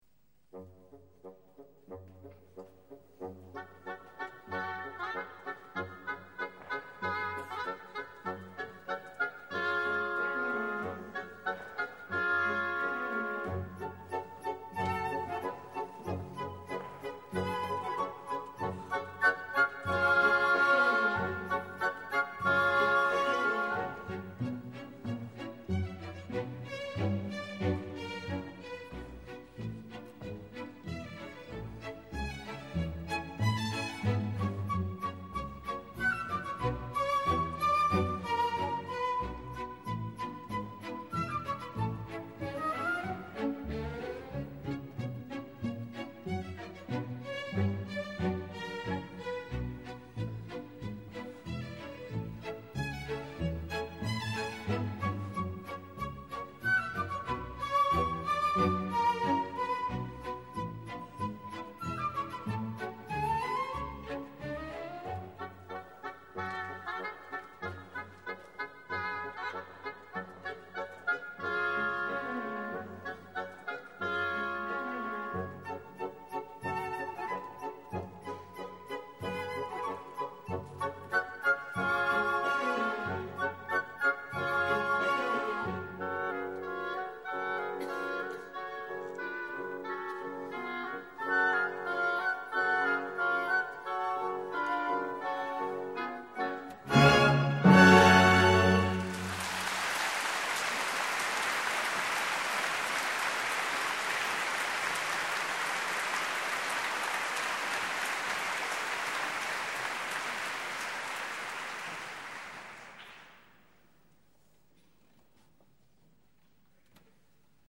De muziek bij de voorstelling van Het Zwanenmeer wordt gespeeld door Het Balletorkest.